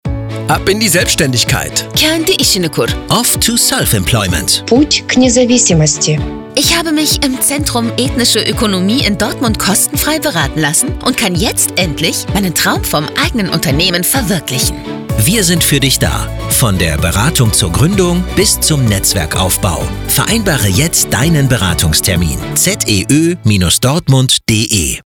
Radiospot 91.2